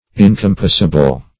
Incompossible \In`com*pos"si*ble\, a. [Pref. in- not +